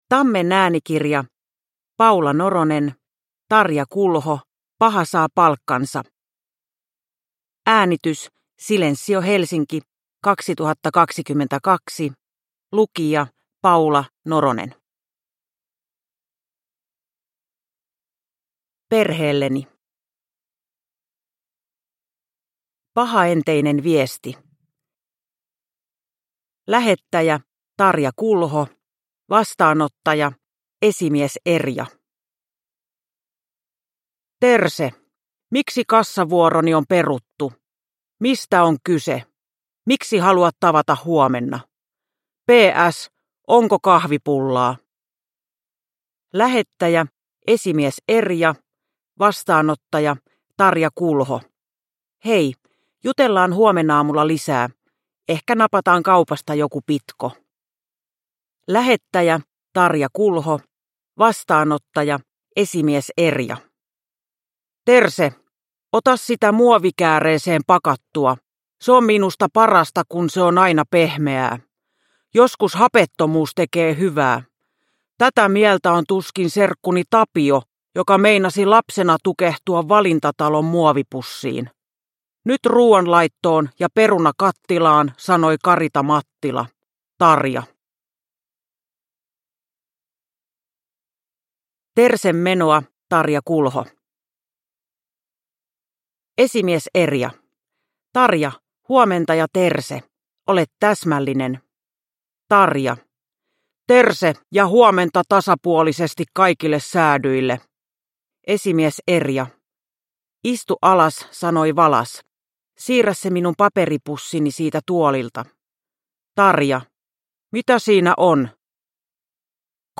Tarja Kulho – Paha saa palkkansa – Ljudbok – Laddas ner